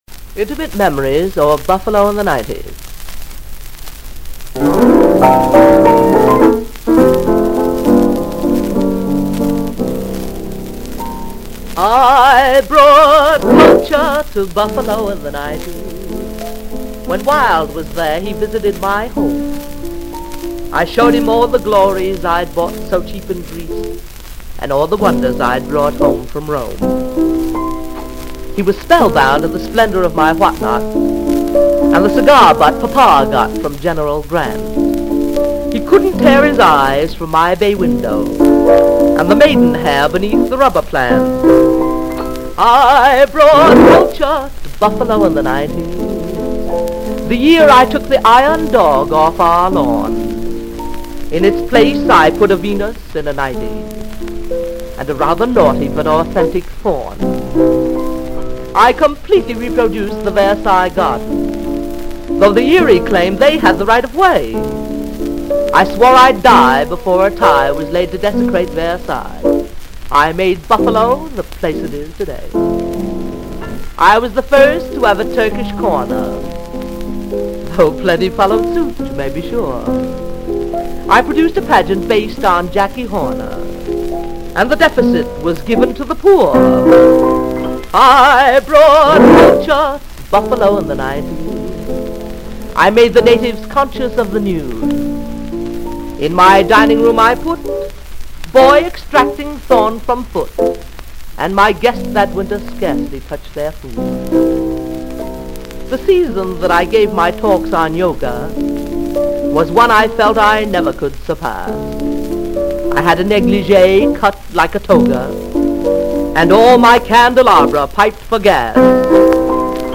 --w. piano, New York City, Oct., 1939 Reeves Sound Studios.